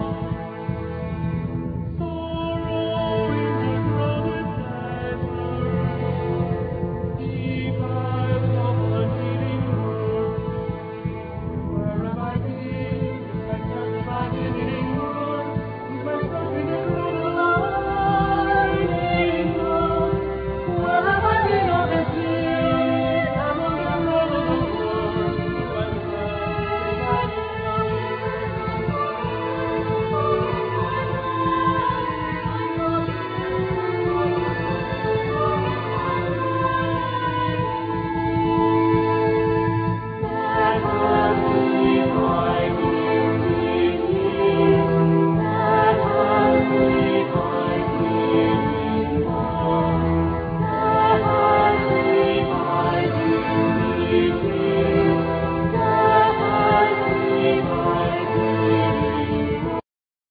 Soprano
Mezzo Soprano
Tennor
Piano
Orchestra